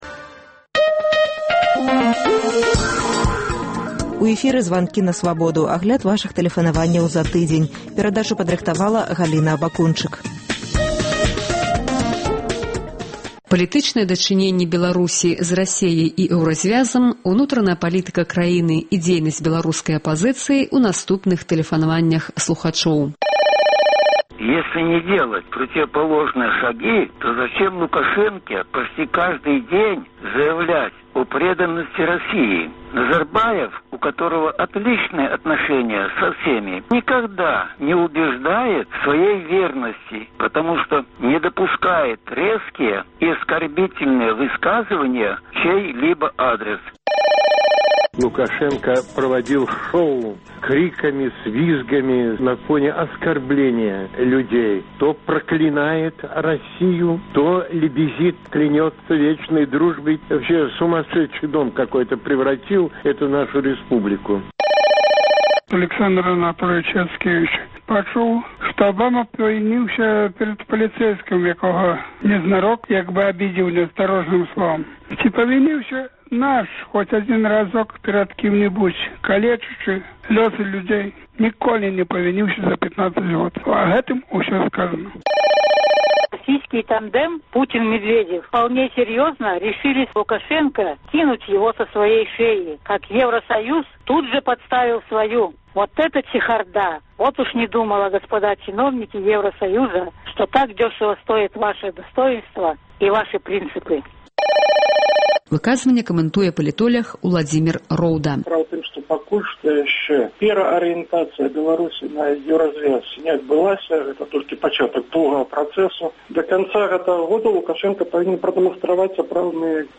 Агляд вашых тэлефанаваньняў за тыдзень